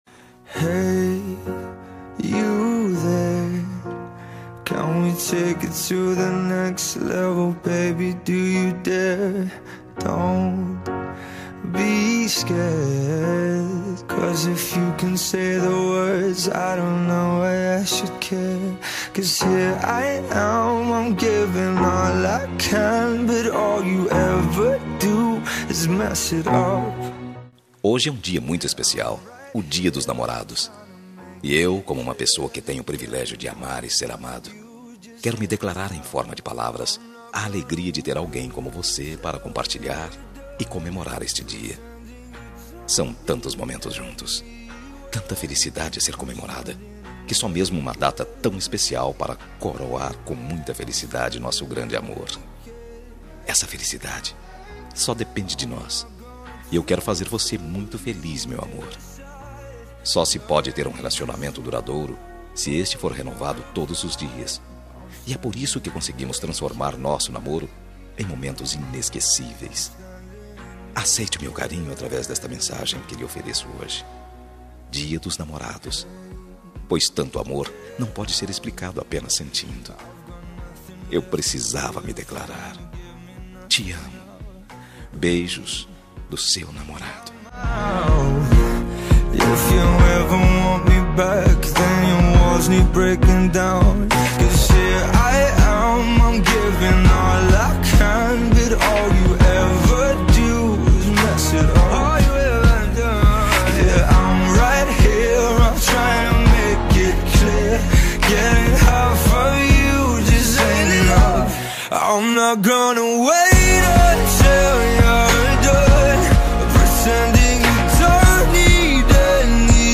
Dia dos Namorados – Para Namorada – Voz Masculina – Cód: 6869